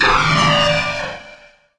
AmpFire2.ogg